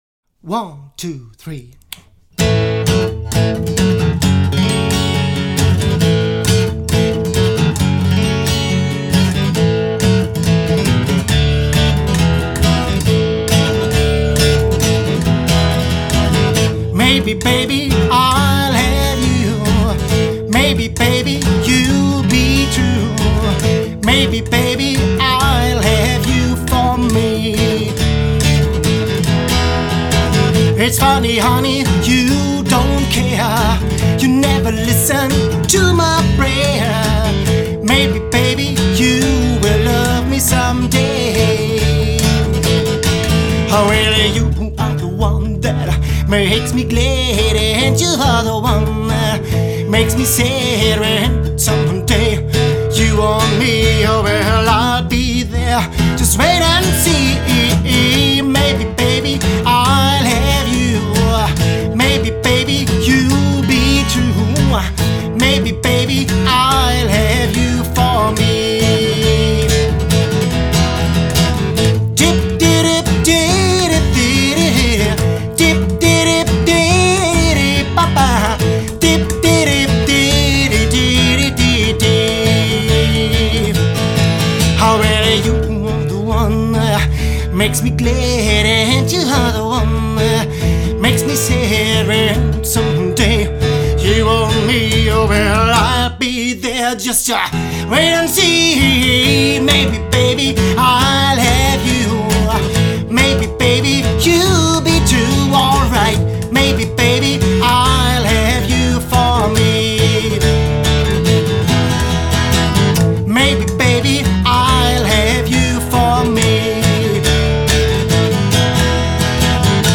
Good Rockin Acoustic Music